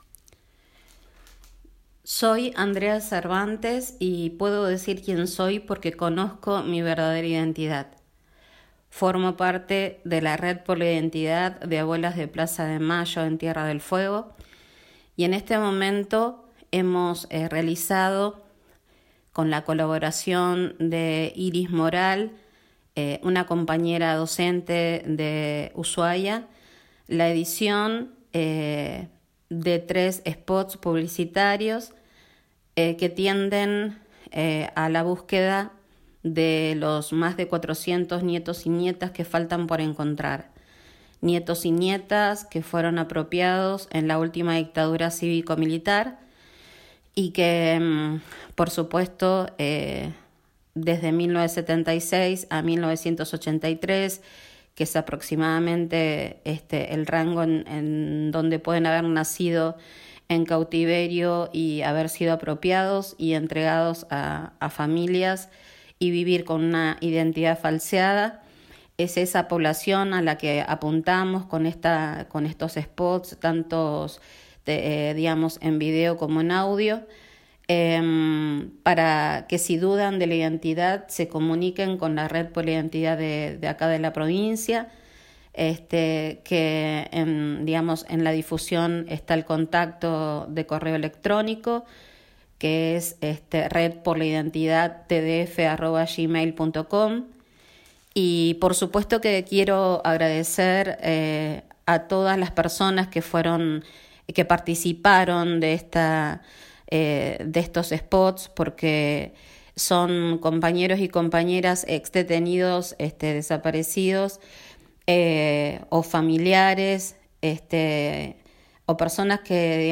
en diálogo con este medio.